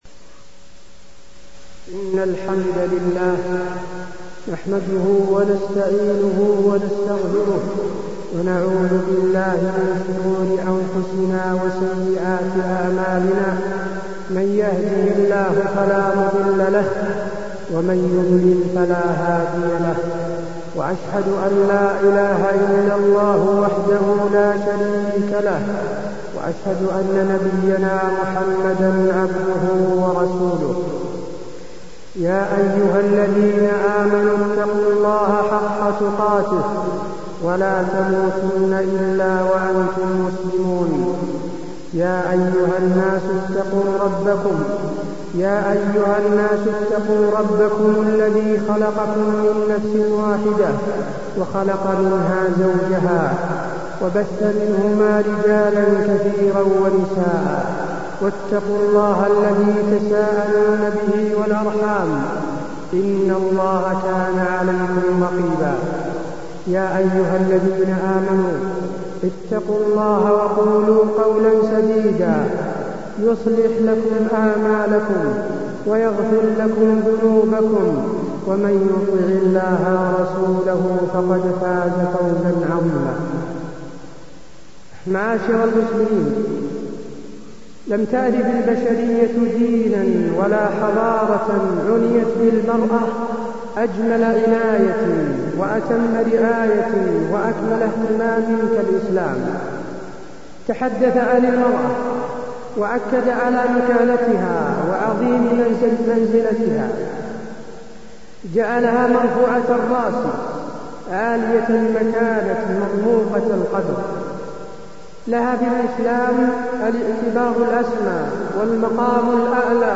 تاريخ النشر ٢١ جمادى الآخرة ١٤٢٣ هـ المكان: المسجد النبوي الشيخ: فضيلة الشيخ د. حسين بن عبدالعزيز آل الشيخ فضيلة الشيخ د. حسين بن عبدالعزيز آل الشيخ مكانة المرأة في الإسلام